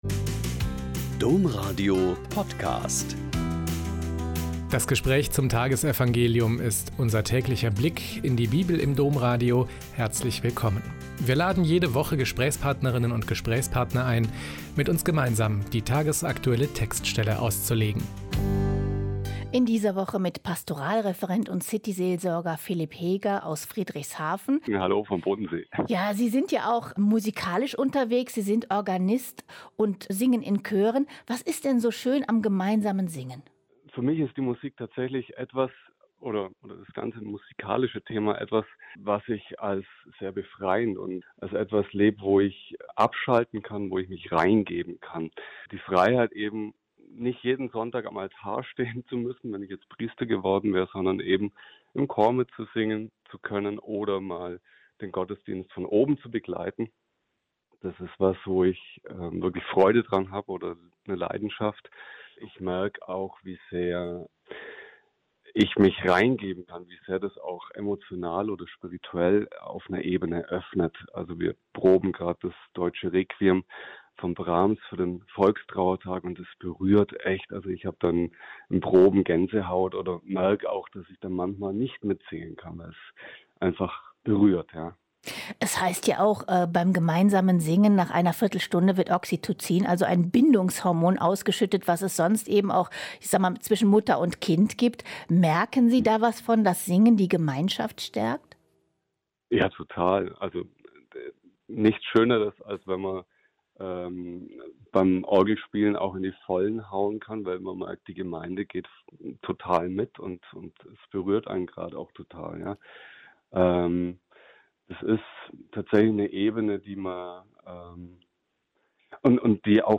Lk 8,4-15 - Gespräch